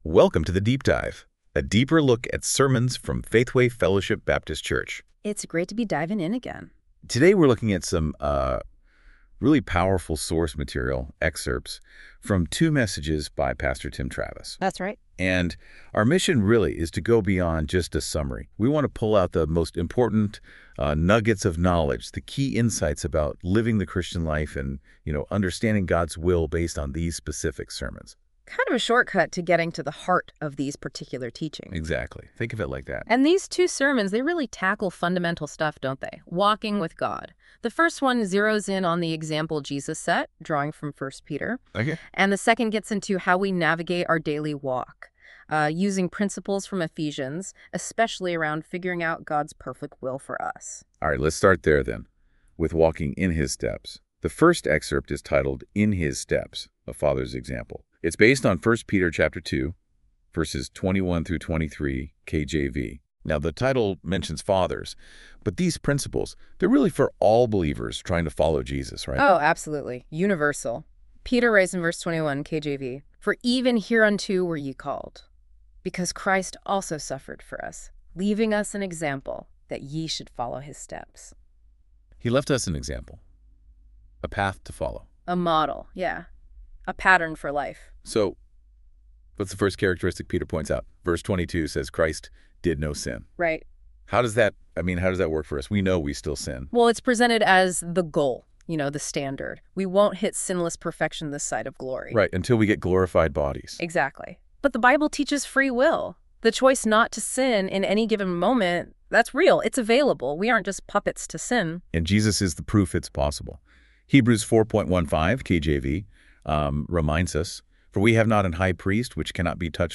This content is AI generated for fun.